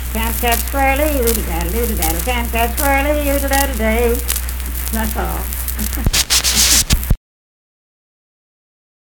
Unaccompanied vocal music performance
Dance, Game, and Party Songs
Voice (sung)
Jackson County (W. Va.)